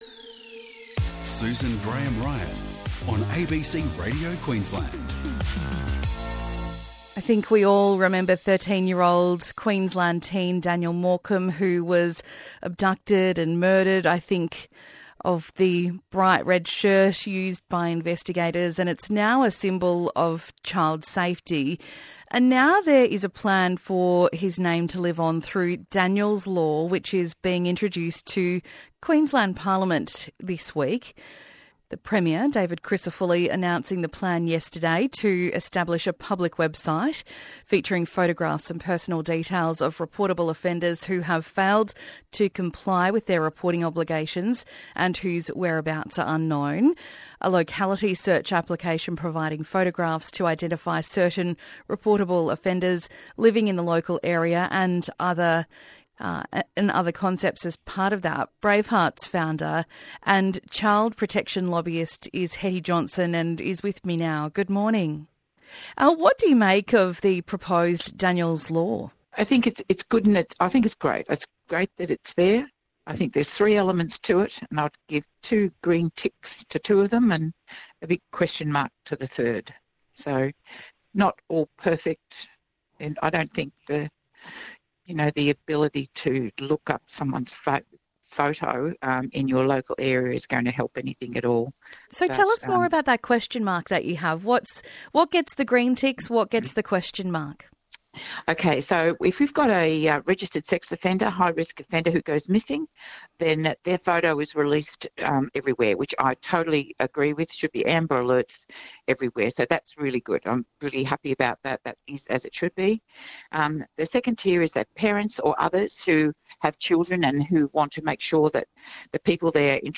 25/8/25 - Listen to ABC Journalist talk to Hetty Johnston about 'Daniel's Law' which is being introduced to Queensland Parliament this week.